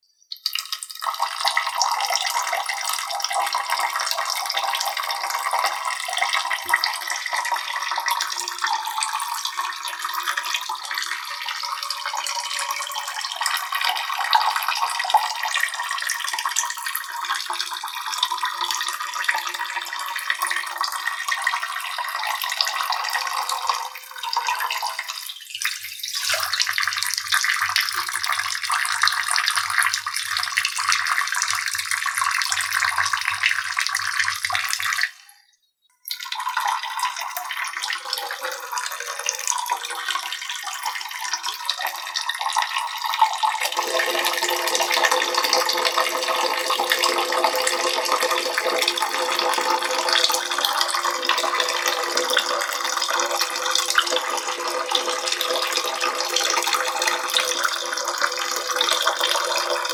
Dźwięki wody do nocnika dla dziecka do siusiania ♡ Jurgle (256 kbps) 38565
• Kategoria: Dźwięki wody do sikania 1195